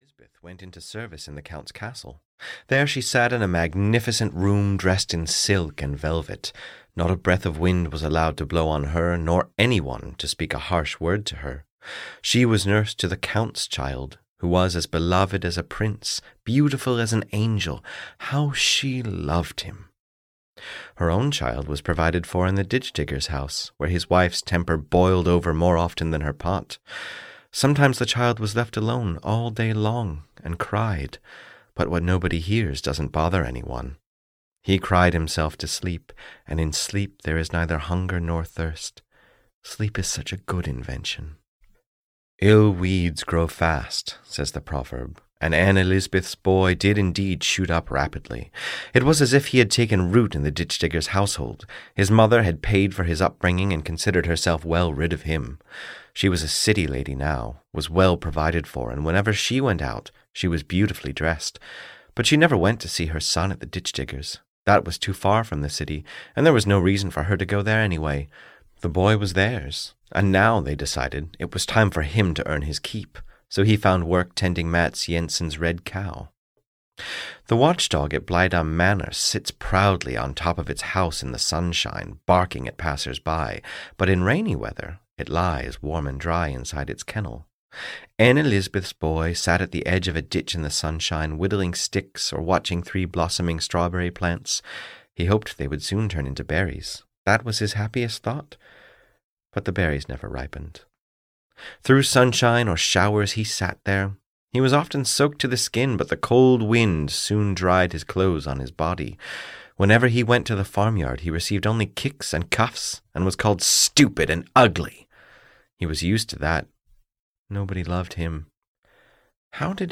Anne Lisbeth (EN) audiokniha
Ukázka z knihy